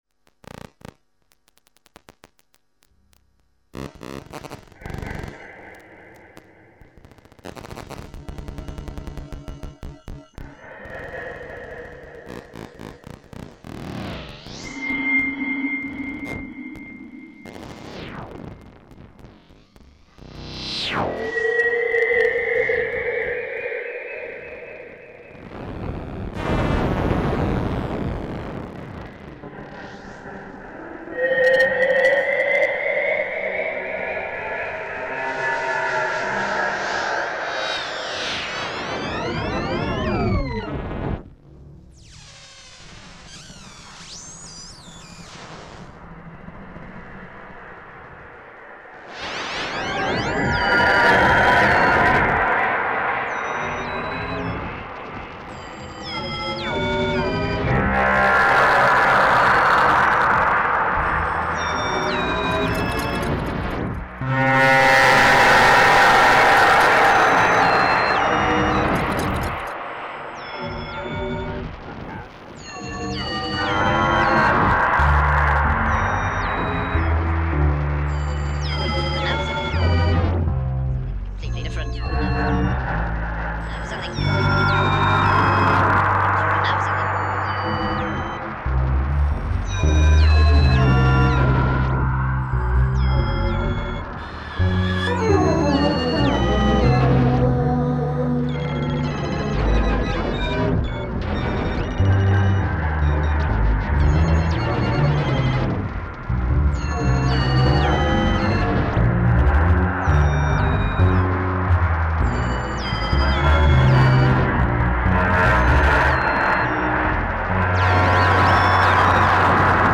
supercollider , stick, radio tuner, system 100 and effect processors. Recorded live on the 21th october 2007 at today's art festival in Den HAAG (NL)